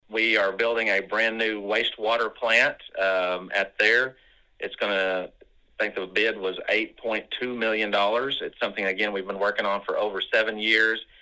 CLICK HERE to listen to commentary from City Manager Kevin Boatright.